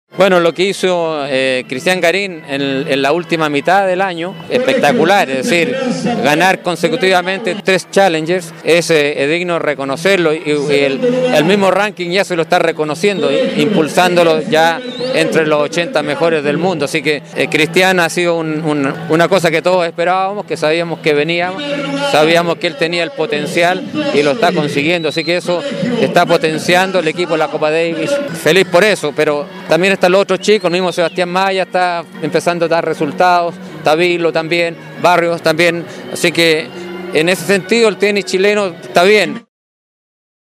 En conversación con BioBioDeportes, Fillol -quien junto a su hermano Álvaro fueron los organizadores del torneo ATP que se disputó entre 1993 y 2014- reconoció que trabajan para retomar la fecha en nuestro país, contando entre otros con el apoyo del Gobierno.